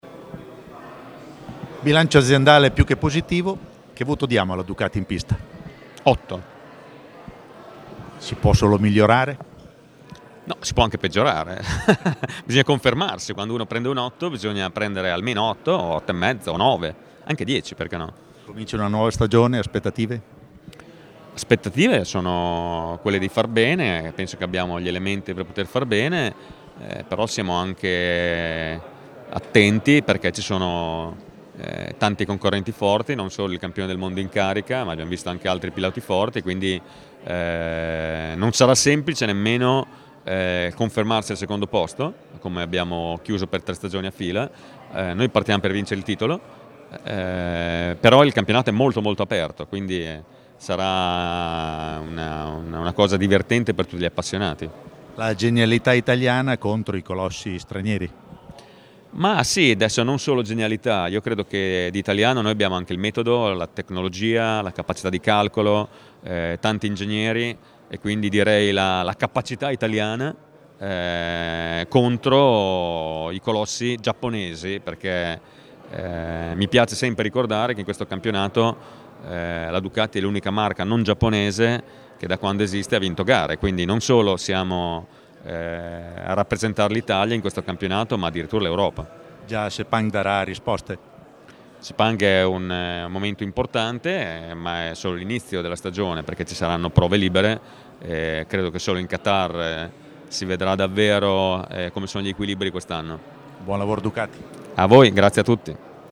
Lo storico Palazzo Re Enzo, in Piazza Maggiore nel centro di Bologna, ha ospitato la presentazione ufficiale del team Mission Winnow Ducati per la stagione 2020.